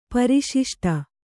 ♪ pari śiṣṭa